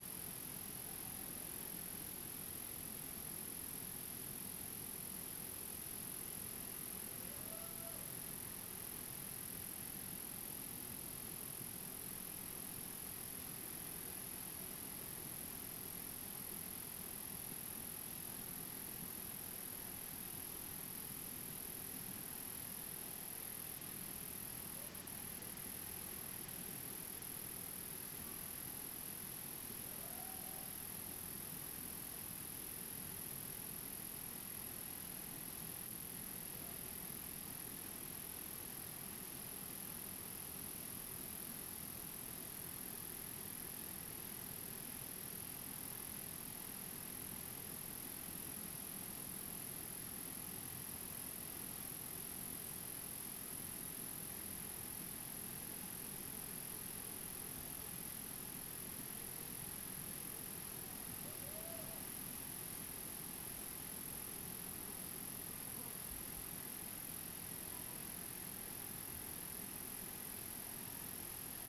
Arquivo de Ambientes Naturais - Página 11 de 11 - Coleção Sonora do Cerrado
CSC-05-018-LE - Ambiencia parque terra viva dentro do mato com muitos grilos, cachoeira e pessoas longe.wav